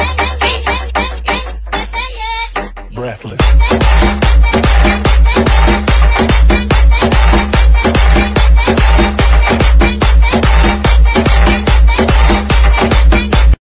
Funny Ringtones